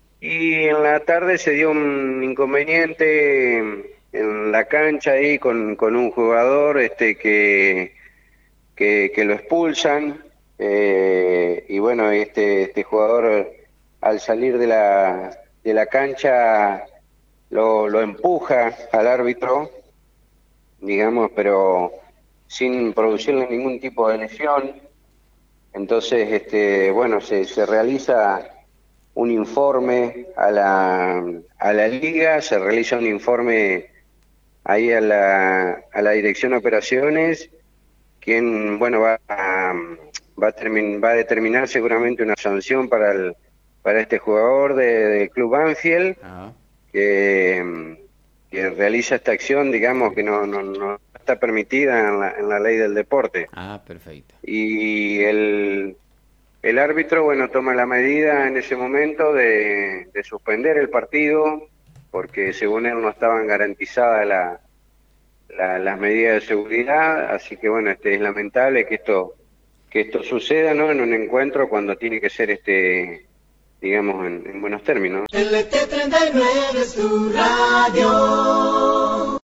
Jefe de Policía Omar Regondi